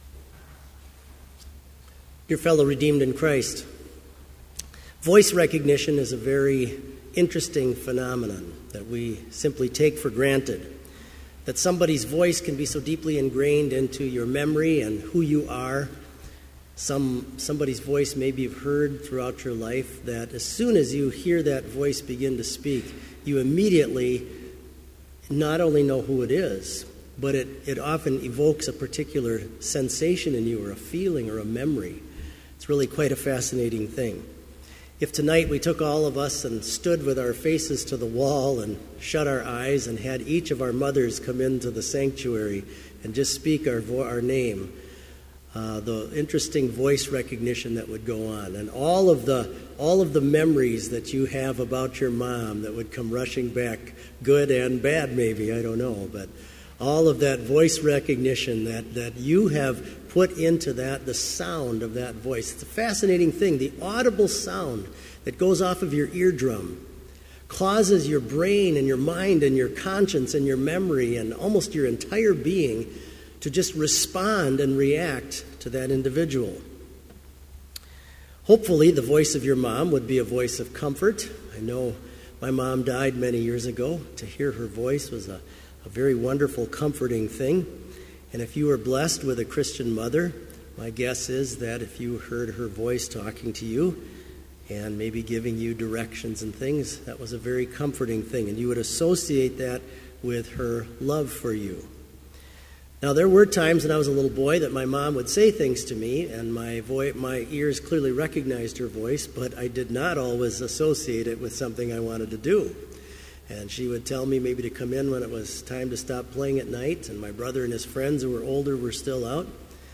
Sermon Only
This Vespers Service was held in Trinity Chapel at Bethany Lutheran College on Wednesday, April 22, 2015, at 5:30 p.m. Page and hymn numbers are from the Evangelical Lutheran Hymnary.